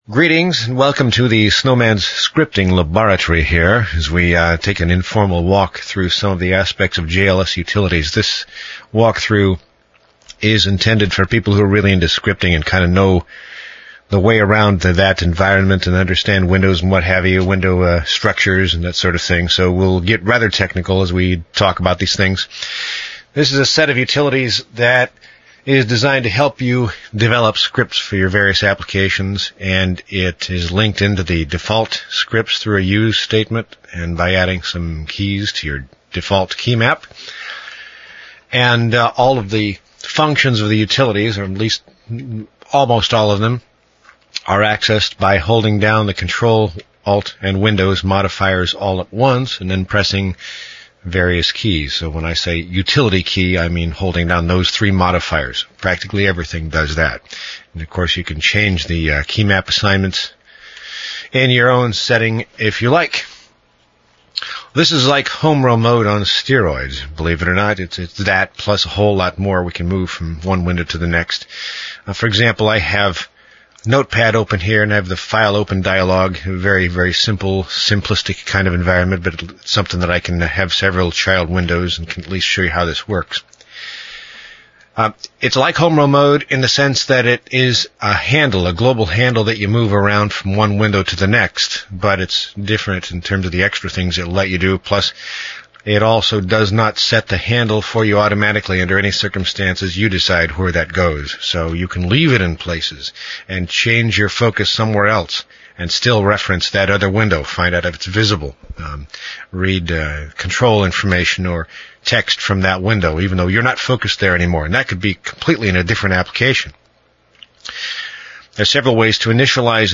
Audio Demo of JLS_utilities Download the scripts here, and run the installer, which will copy in the necessary files,and recompile them for your version of jaws.